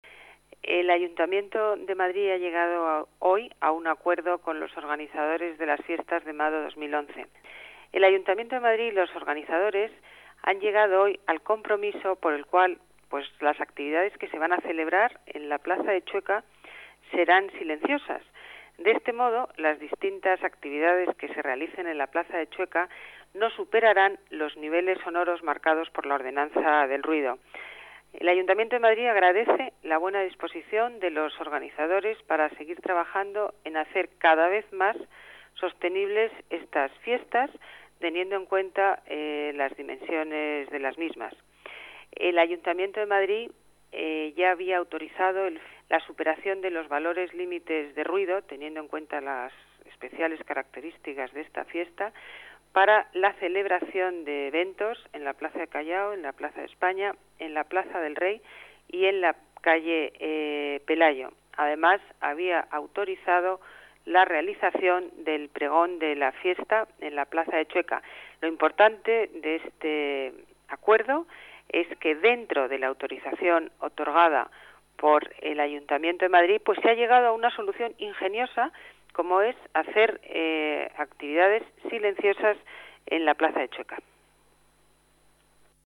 Nueva ventana:Declaraciones teniente de alcalde Ana Botella: acuerdo celebración fiestas Orgullo en plaza de Chueca